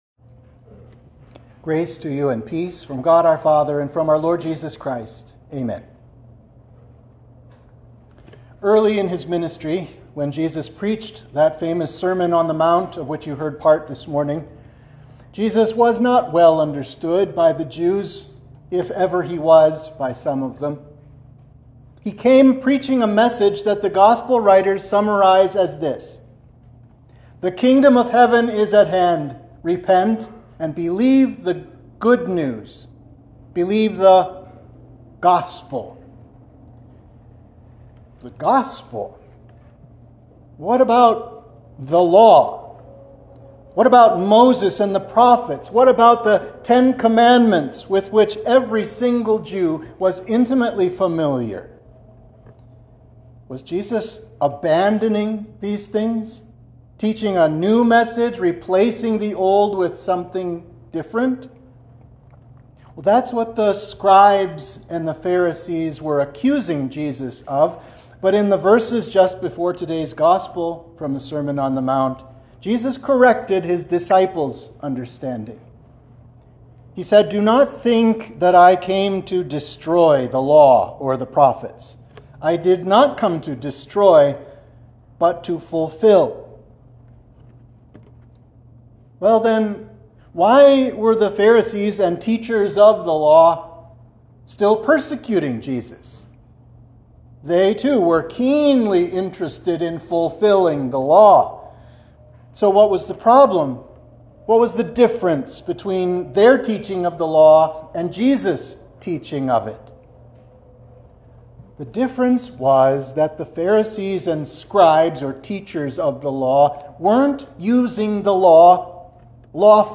Sermon for Trinity 6